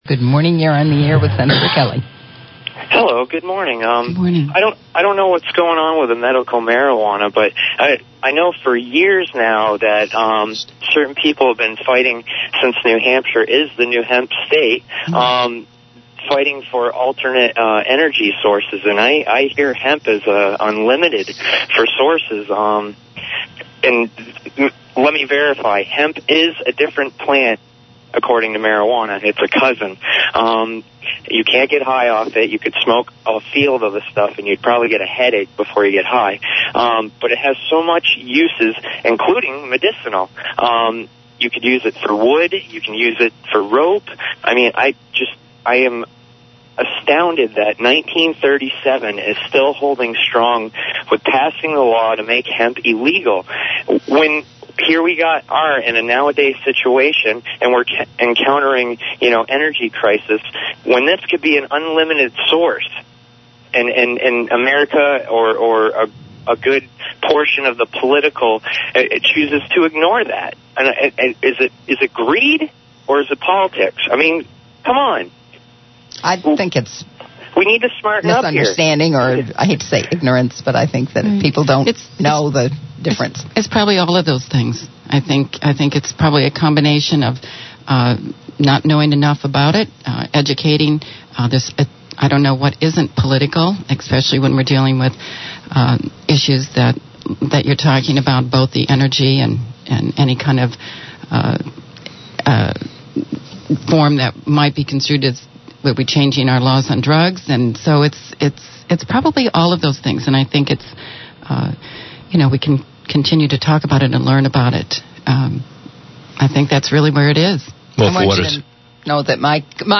The smarmy statist calls in again to level his usual insults and ad hominems at liberty activists.
Please join us in calling the program with a pro-liberty viewpoint.